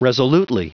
Prononciation du mot resolutely en anglais (fichier audio)